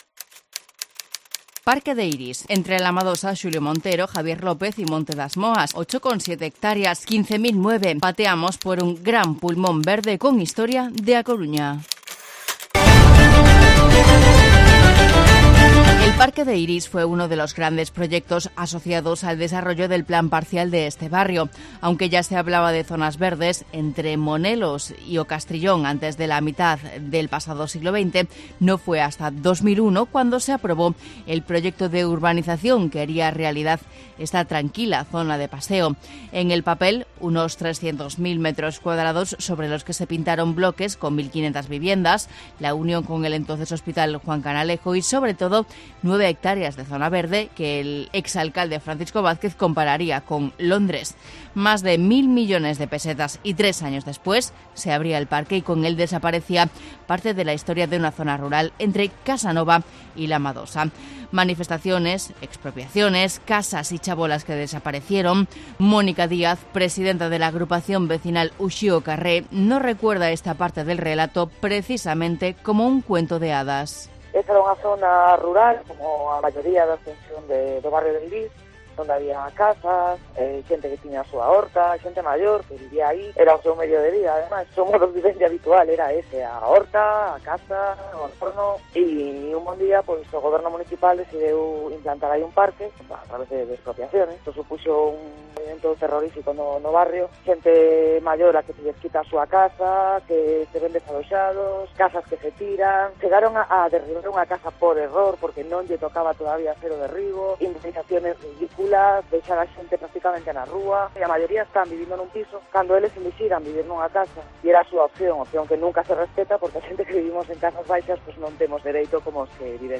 Reportaje sobre el Parque de Eirís en Pateando A Coruña